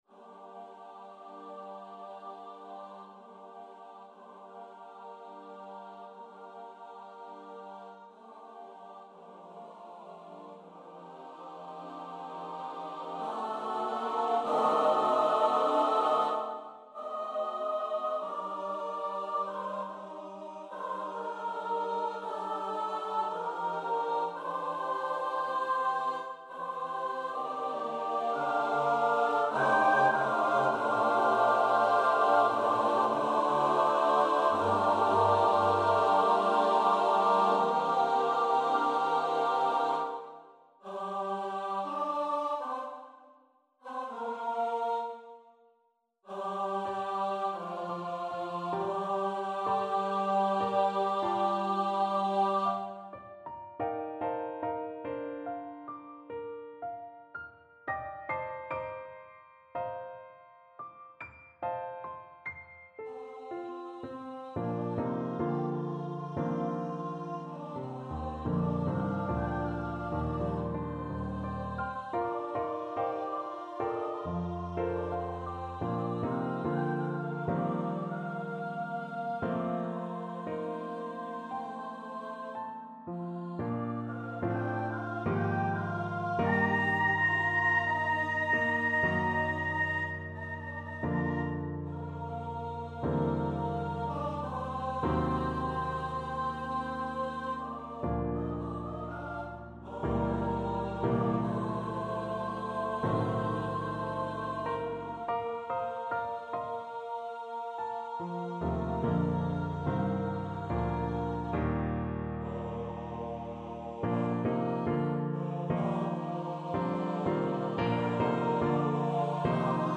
For SATB divisi and Piano
Midi demo